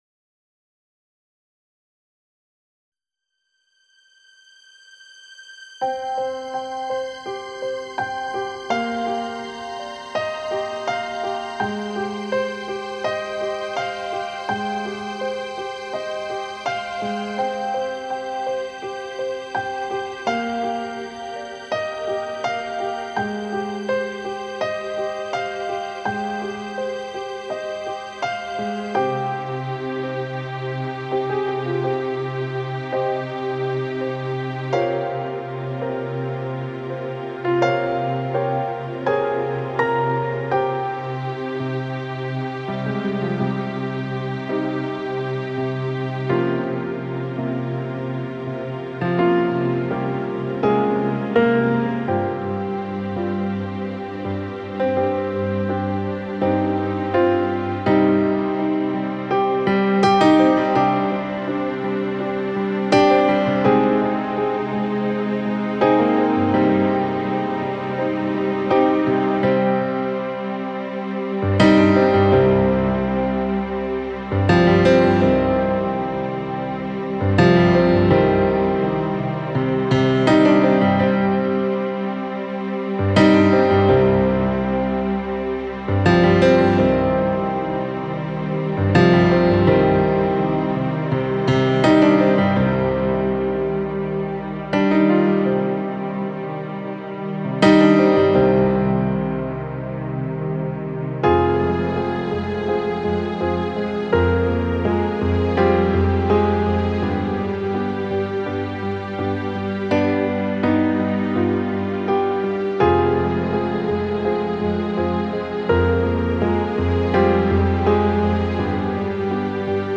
Allegro [120-130] tristesse - piano - songe - doux - calme
songe - doux - calme